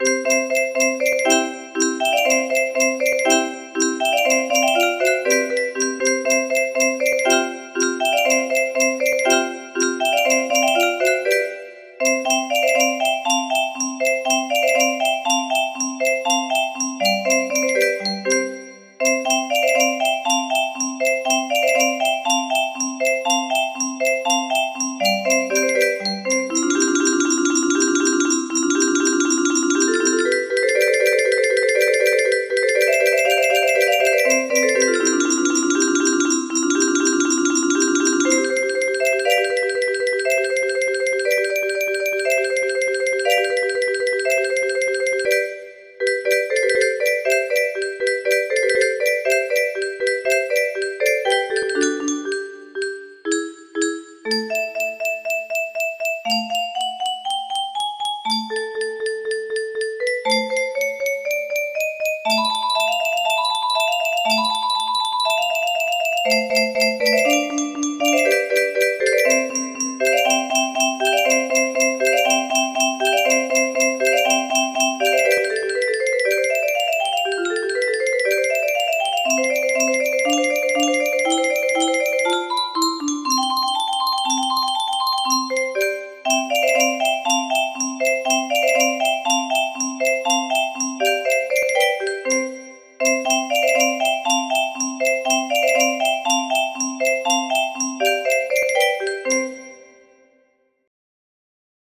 Spring music box melody